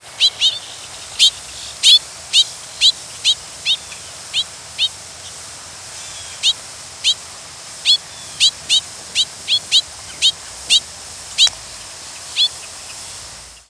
Bobolink diurnal
Glossary Species Home Bobolink diurnal flight calls Fig.2. New York July 17, 1989 (WRE).
Several birds in flight with Boat-tailed Grackle and Gray Catbird calling in the background.